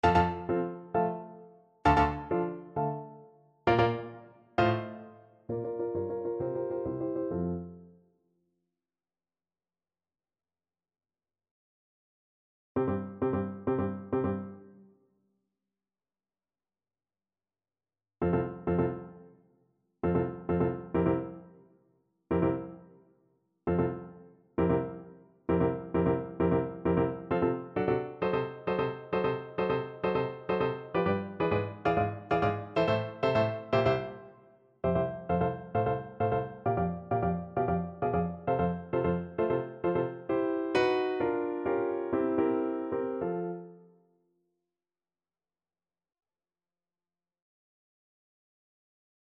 Play (or use space bar on your keyboard) Pause Music Playalong - Piano Accompaniment Playalong Band Accompaniment not yet available transpose reset tempo print settings full screen
F major (Sounding Pitch) G major (Trumpet in Bb) (View more F major Music for Trumpet )
2/2 (View more 2/2 Music)
Allegro agitato e appassionato assai = 132 (View more music marked Allegro)
Classical (View more Classical Trumpet Music)